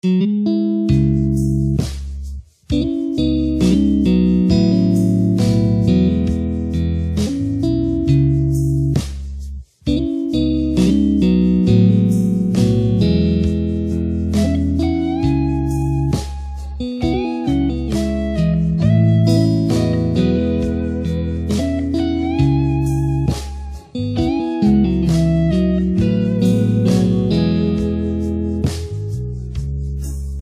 Audio de la tablature complète :
Accordage : Standard